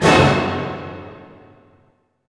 Index of /90_sSampleCDs/Roland LCDP08 Symphony Orchestra/HIT_Dynamic Orch/HIT_Orch Hit Dim
HIT ORCHD08L.wav